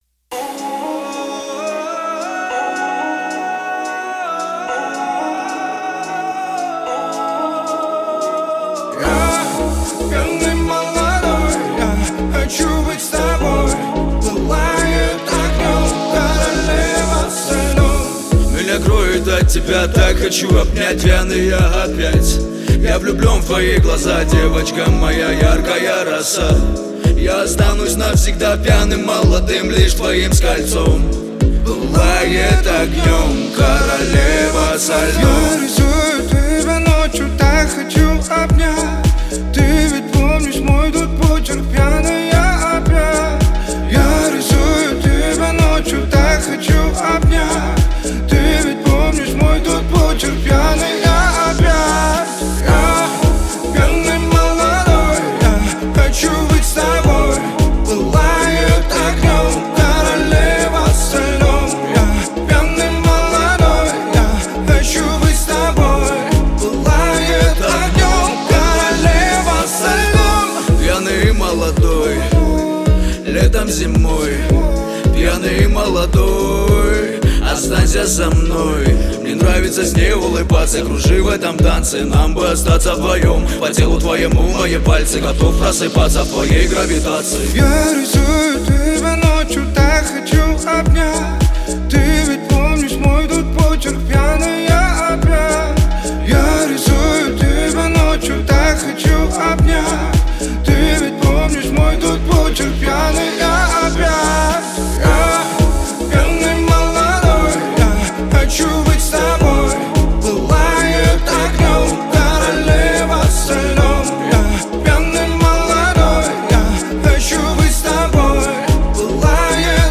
элементы поп и электронной музыки